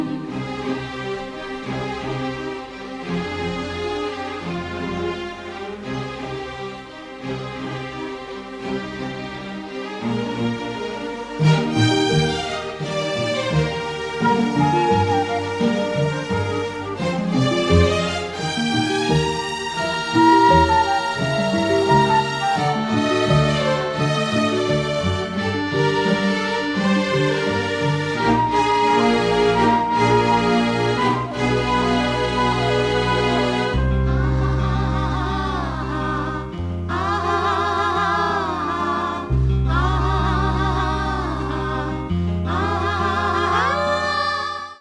Interlude 1
pps: Both the interludes end with a female chorus.